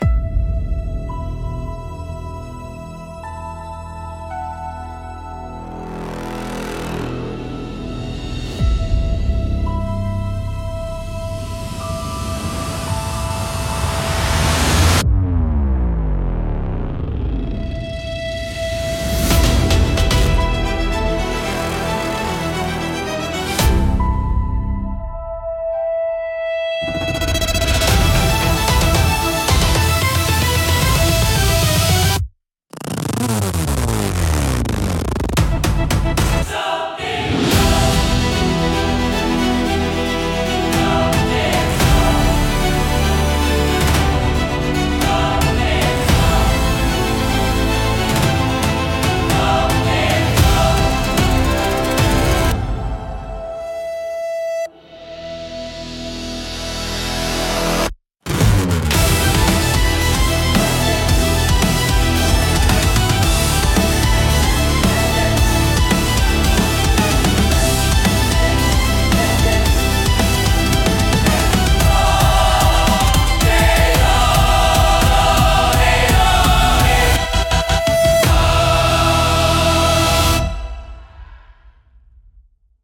Cinematic Trailer Music / Action
Mood: Epic, Intense, Suspenseful, Action